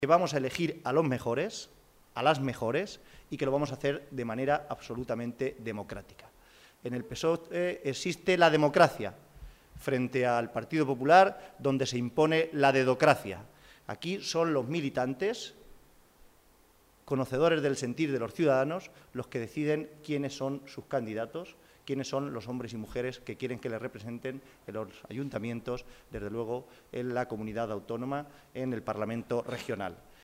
Momento de la rueda de prensa celebrada en la sede del PSOE de Albacete